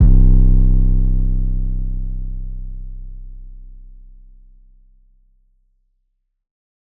808 [ Boominati ].wav